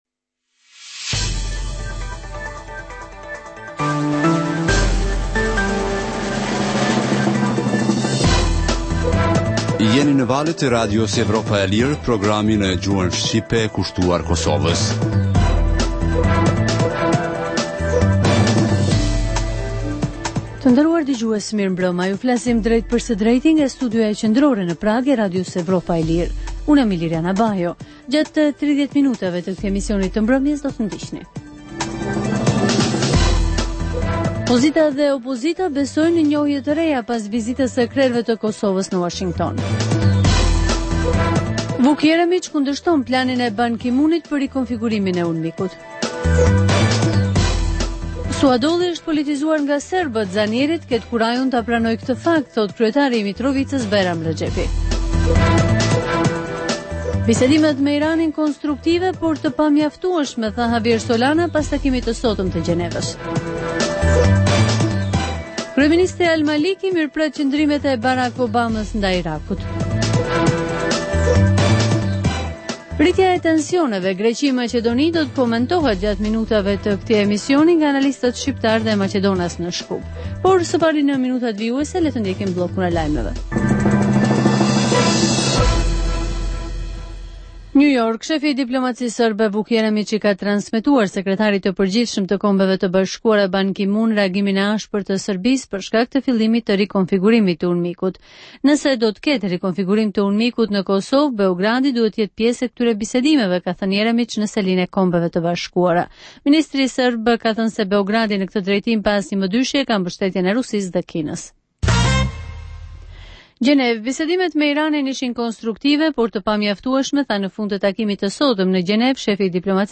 Emisioni i orës 21:00 është rrumbullaksim i zhvillimeve ditore në Kosovë, rajon dhe botë. Rëndom fillon me buletinin e lajmeve dhe vazhdin me kronikat për zhvillimet kryesore politike të ditës. Në këtë edicion sjellim intervista me analistë vendor dhe ndërkombëtar për zhvillimet në Kosovë, por edhe kronika dhe tema aktuale dhe pasqyren e shtypit ndërkombëtar.